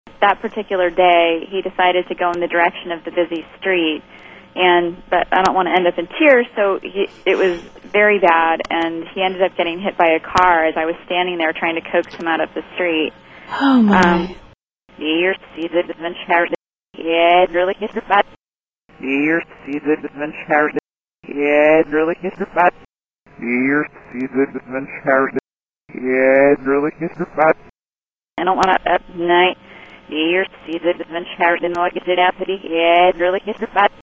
Tags: Reverse Speech Analysis Reverse Speech samples Reverse Speech clips Reverse Speech sounds Reverse Speech